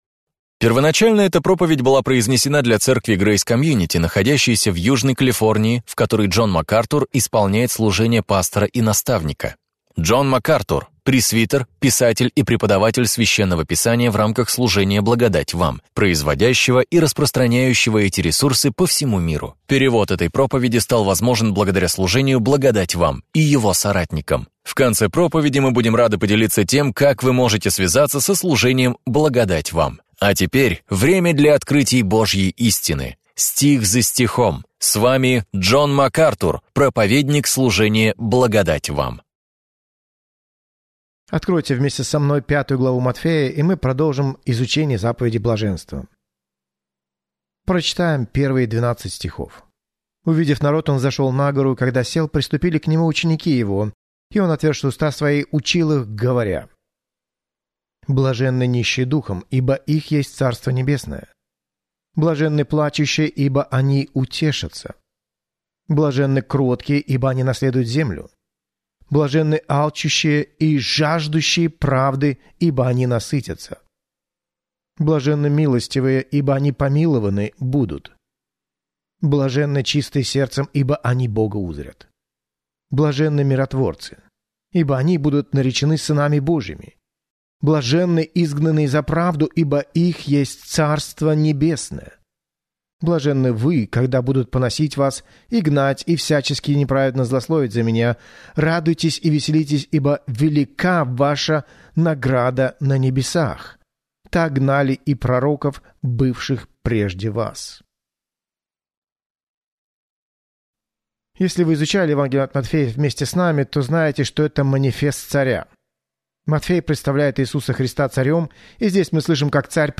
Можем ли мы обладать истинным непреходящим счастьем? В своей проповеди «Заповеди блаженства» Джон Макартур делает обзор утверждений Христа – заповедей блаженства, исследующих моральные, этические и духовные предписания, которыми руководствуются Божьи люди.